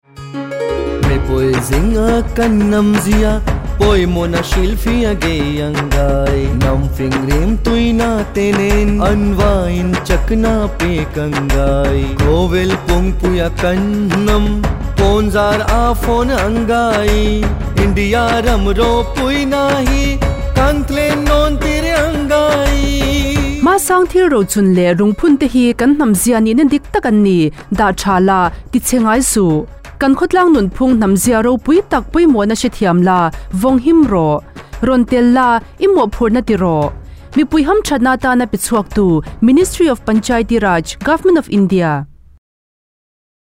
162 Fundamental Duty 6th Fundamental Duty Preserve composite culture Radio Jingle Mizo